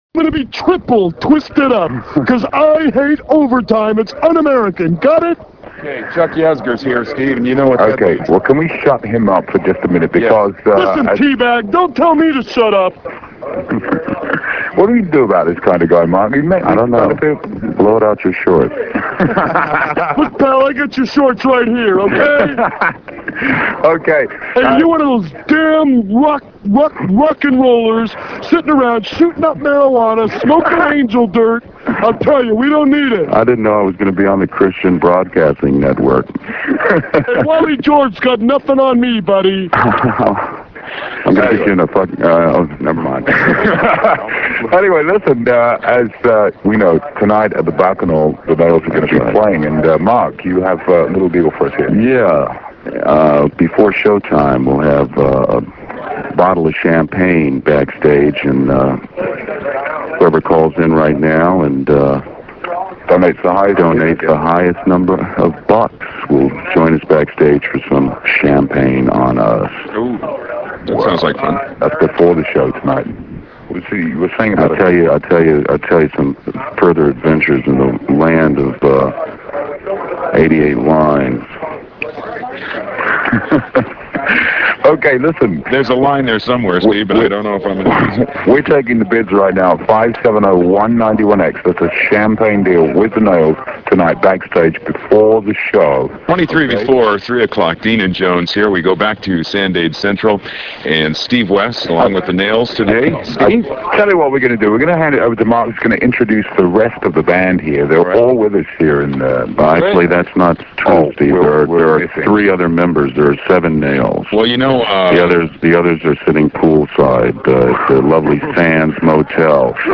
Interview and fund-raiser on 91X in San Diego